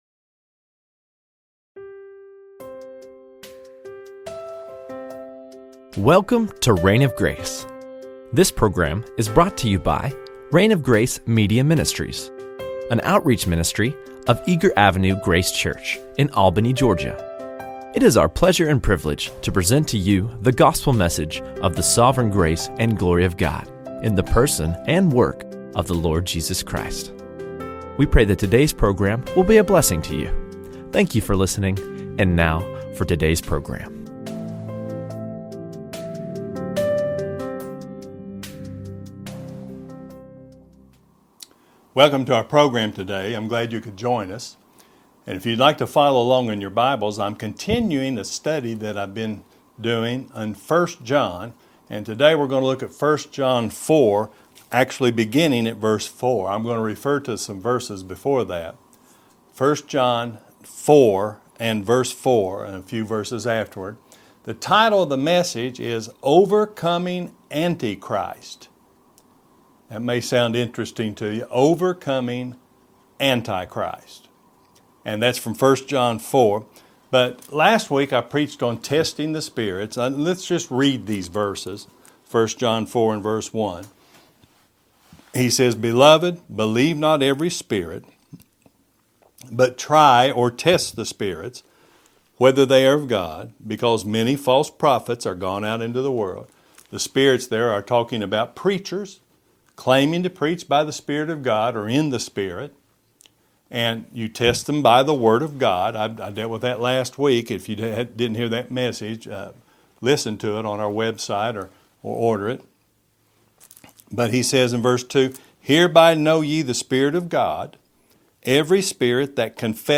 Overcoming Antichrists | SermonAudio Broadcaster is Live View the Live Stream Share this sermon Disabled by adblocker Copy URL Copied!